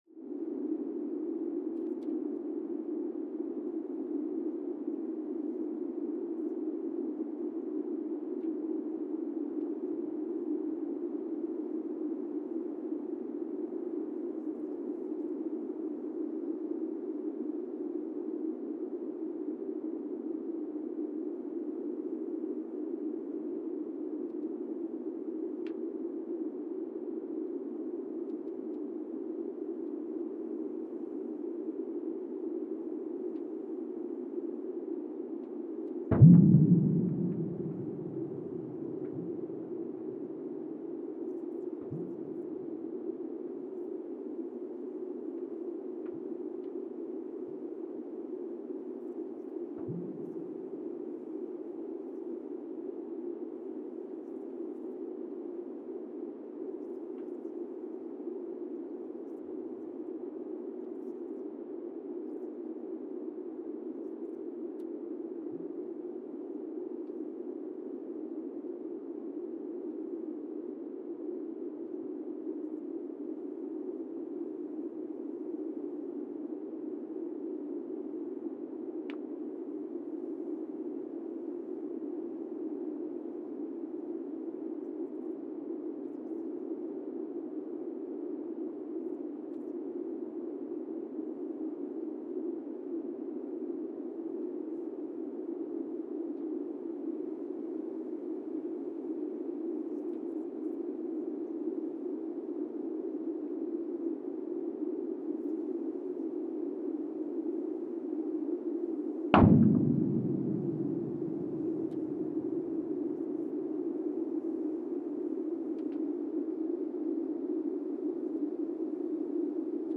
The Earthsound Project: Monasavu, Fiji (seismic) archived on March 8, 2023
No events.
Sensor : Teledyne Geotech KS-54000 borehole 3 component system
Speedup : ×1,800 (transposed up about 11 octaves)
Loop duration (audio) : 05:36 (stereo)